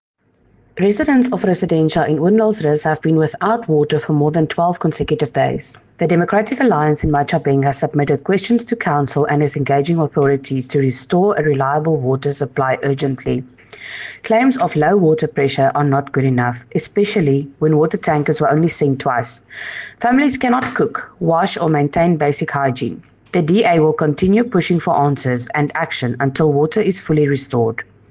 Afrikaans soundbites by Cllr Estelle Dansey and Sesotho soundbite by Cllr Kabelo Moreeng.